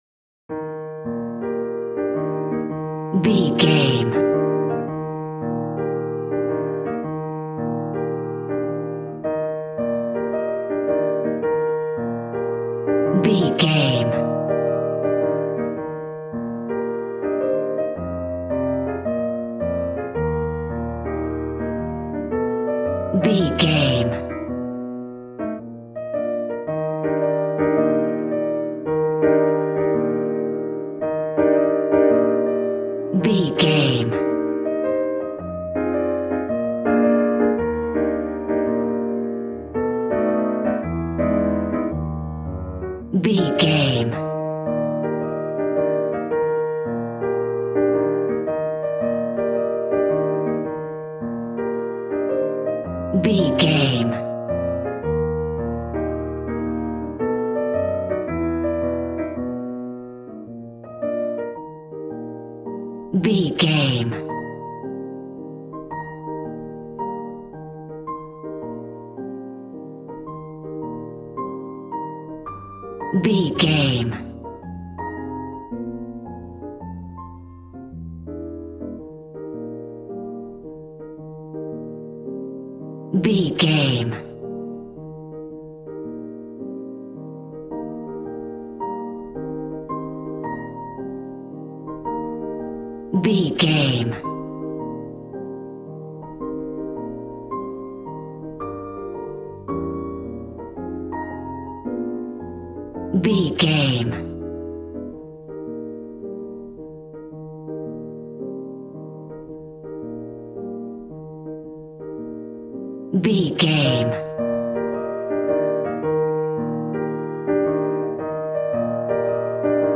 Smooth jazz piano mixed with jazz bass and cool jazz drums.,
Ionian/Major
E♭